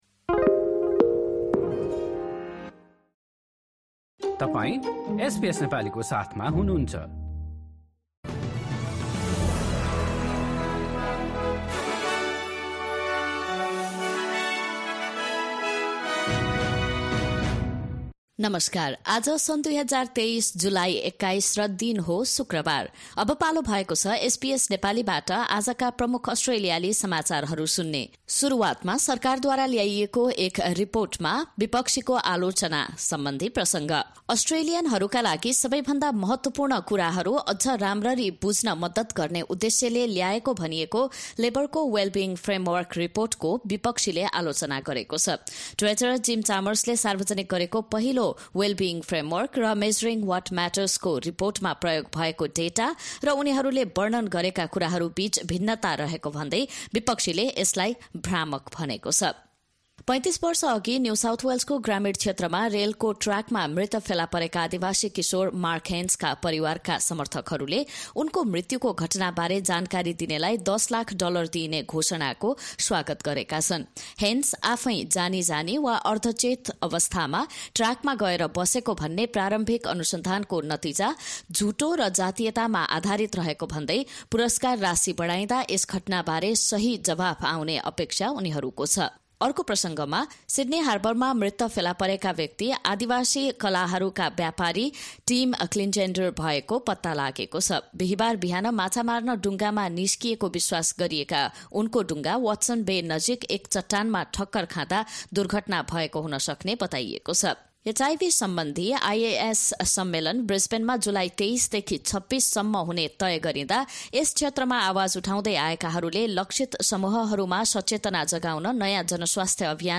एसबीएस नेपाली प्रमुख अस्ट्रेलियाली समाचार: शुक्रवार, २१ जुलाई २०२३